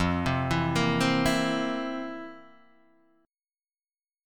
F Major 7th Suspended 4th